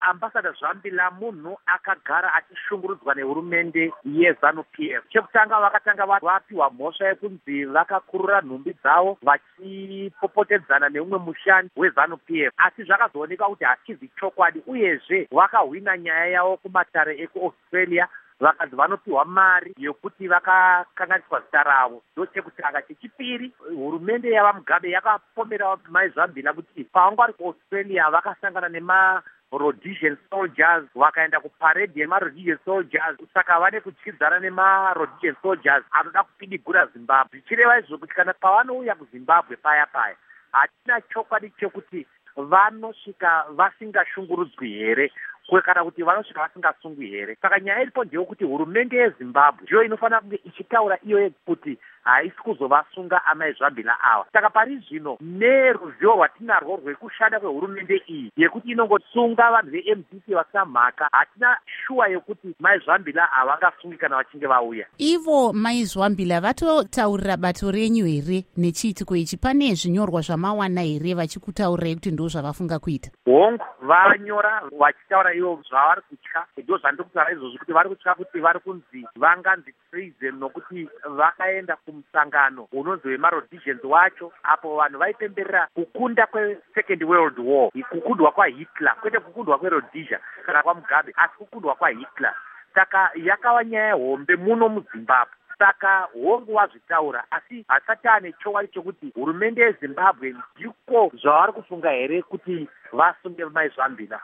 Hurukuro NaVa Douglas Mwonzora